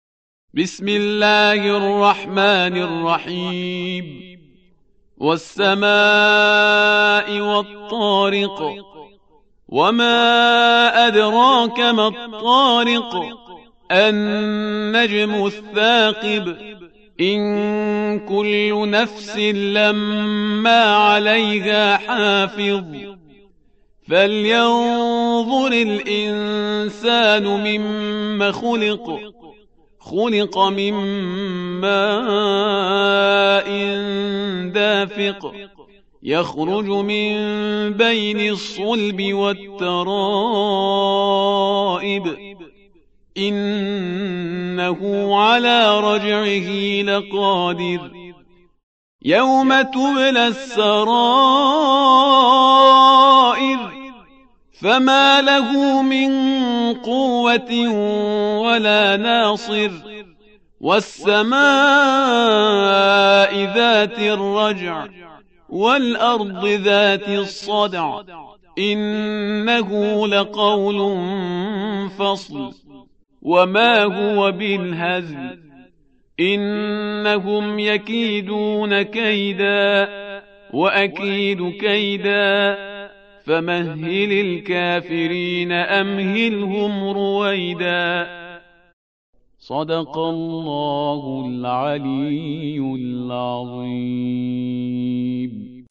قاریان مختلفی سورة طارق صوتی را تلاوت کرده‌اند.